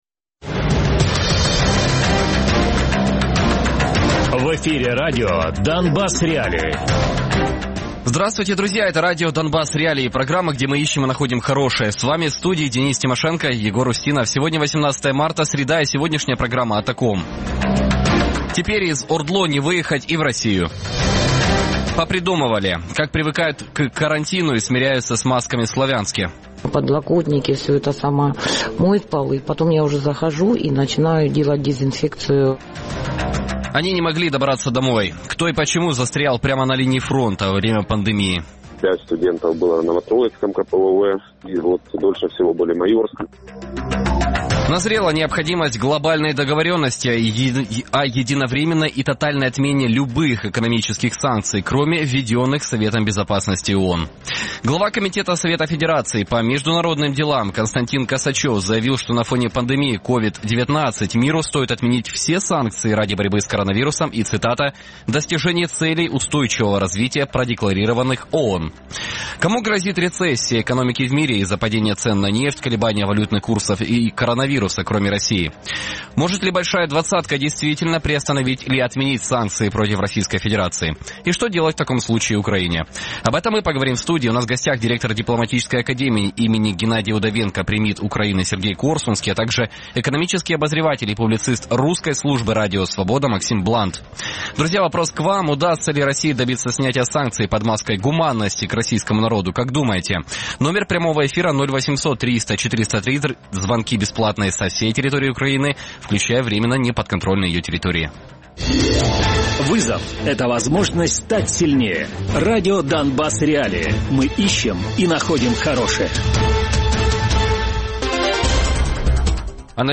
Радіопрограма «Донбас.Реалії» - у будні з 17:00 до 18:00.